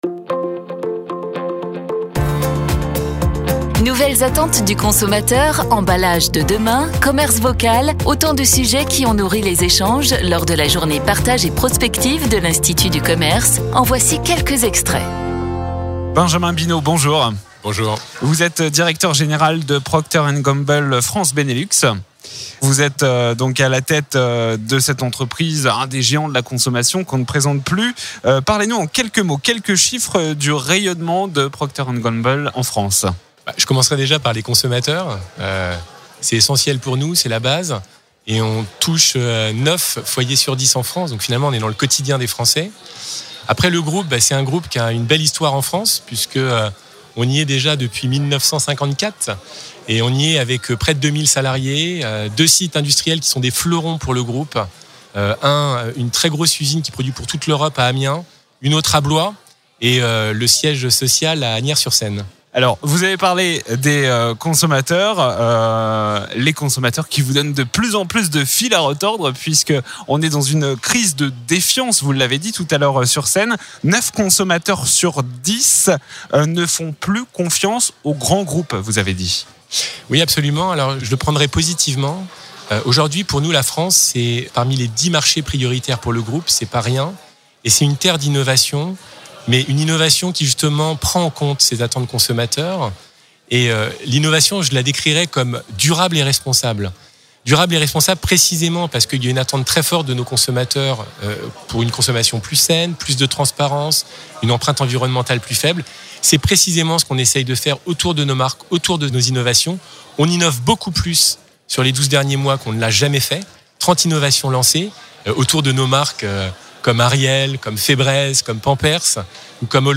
Les interviews Mediameeting de la Journée Partage et Prospective 2019